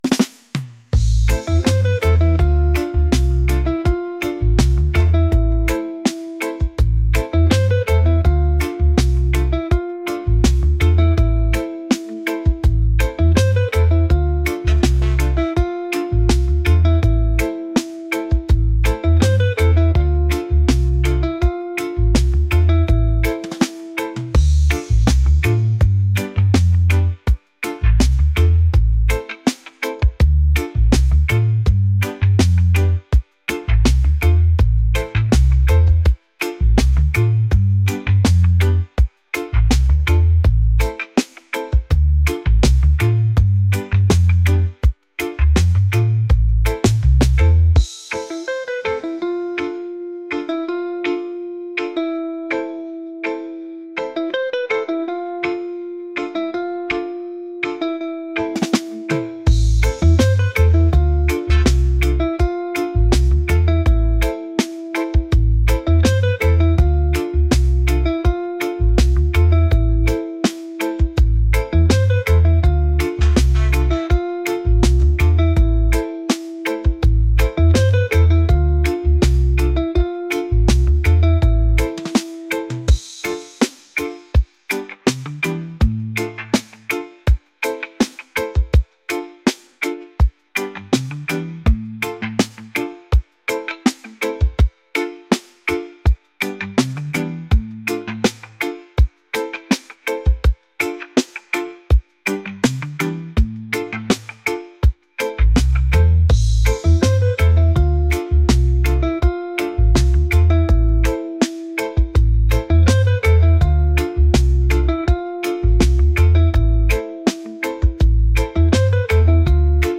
reggae | groovy | smooth